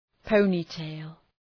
Προφορά
{‘pəʋnı,teıl}